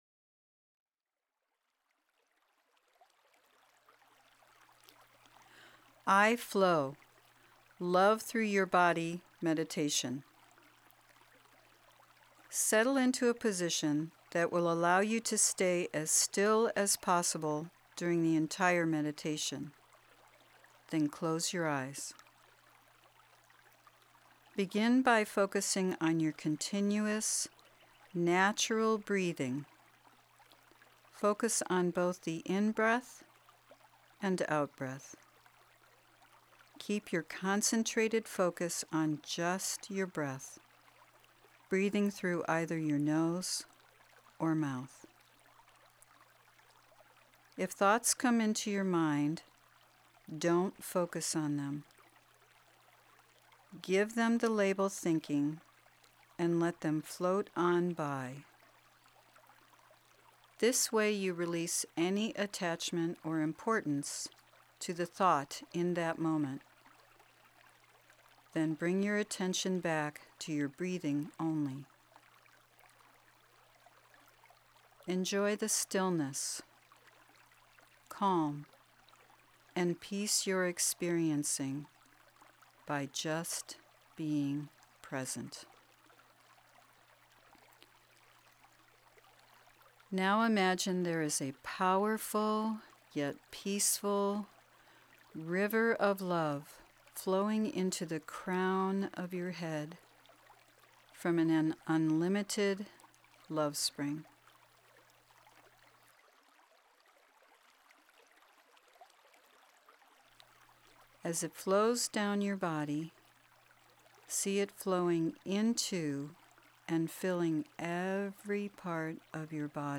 Download Meditation MP3